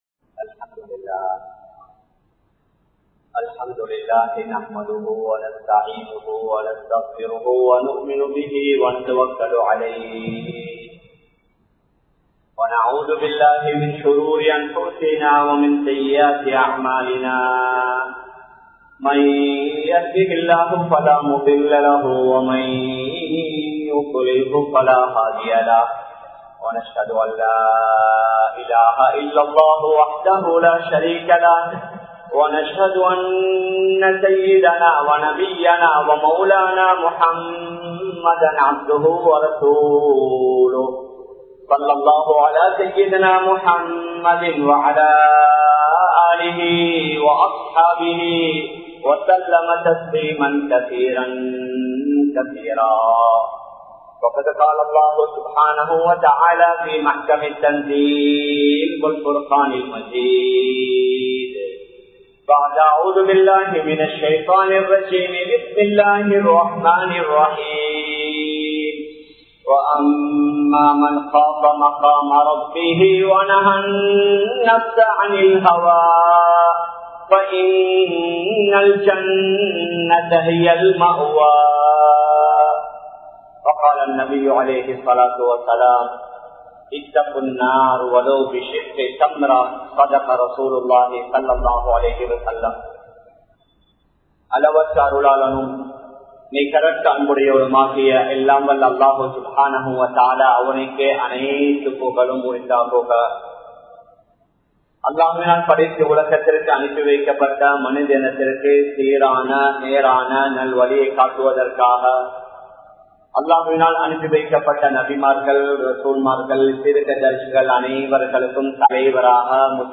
Suvarkaththin Inpangal (சுவர்க்கத்தின் இன்பங்கள்) | Audio Bayans | All Ceylon Muslim Youth Community | Addalaichenai
Kollupitty Jumua Masjith